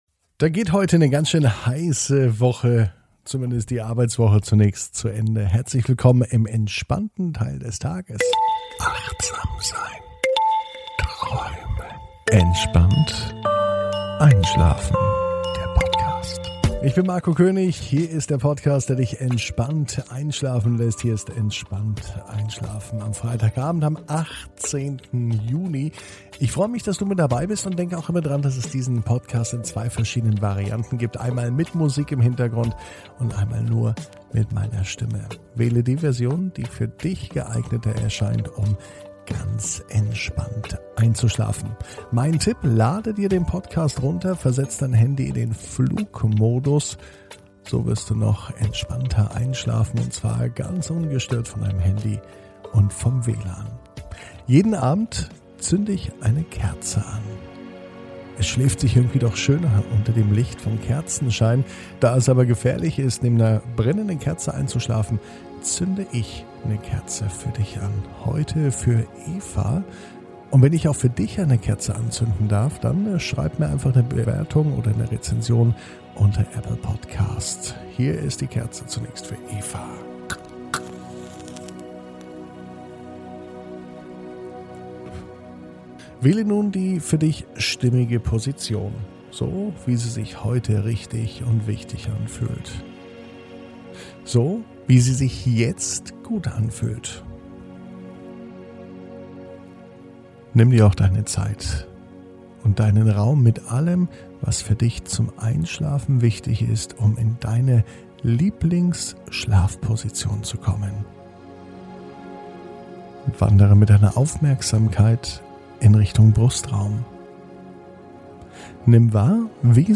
Entspannt einschlafen am Freitag, 18.06.21 ~ Entspannt einschlafen - Meditation & Achtsamkeit für die Nacht Podcast